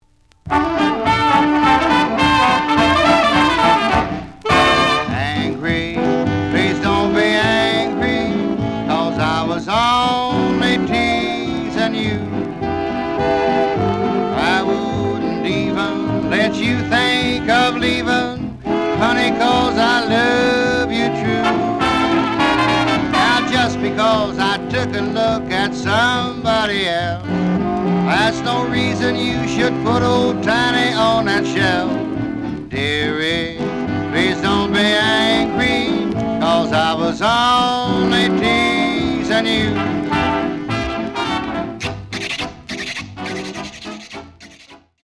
The audio sample is recorded from the actual item.
●Format: 7 inch
●Genre: Vocal Jazz